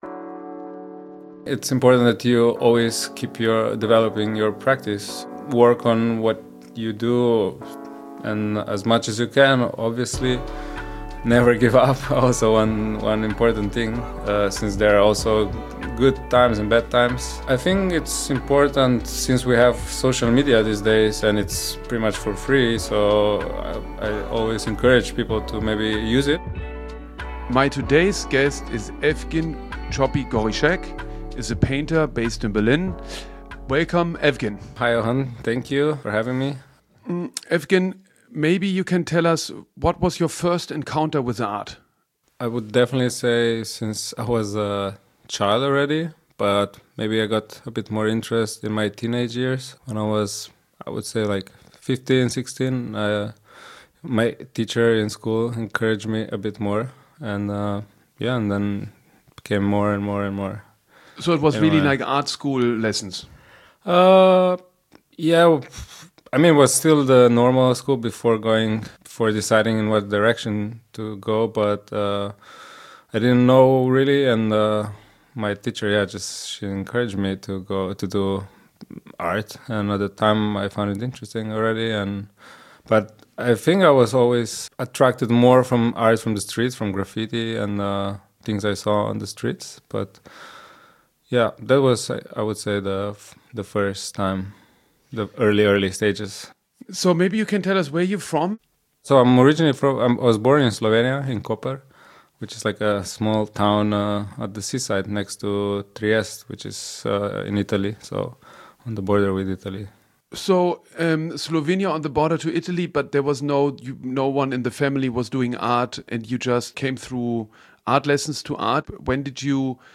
In “Was mit Kunst” lichtet Johann König den Schleier der exklusiven Kunstwelt, indem er Künstler*innen, Kurator*innen und Kunstsammler*innen interviewt. Die Gäste sprechen über ihren Werdegang, ihre Werke und reflektieren über die Entscheidungen, die sie in ihrer Karriere getroffen haben, wie sie Hindernisse überwunden und sich von Zuschreibungen freigemacht haben.